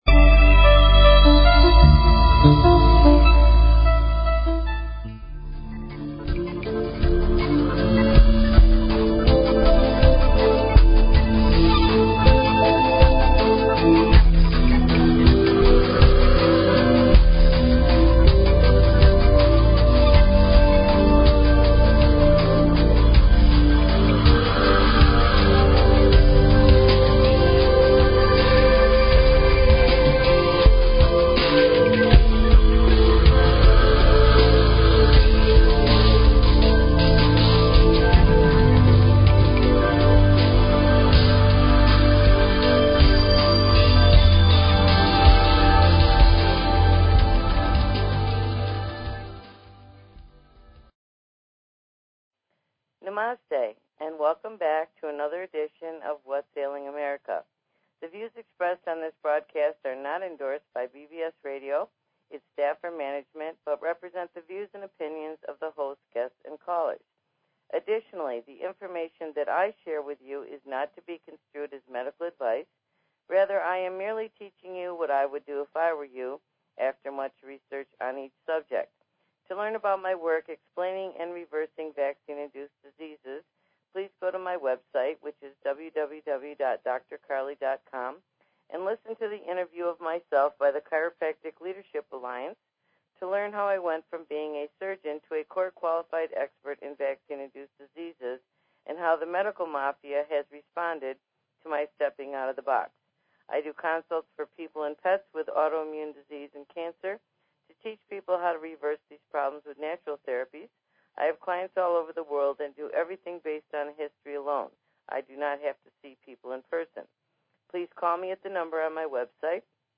Talk Show Episode, Audio Podcast, Whats_Ailing_America and Courtesy of BBS Radio on , show guests , about , categorized as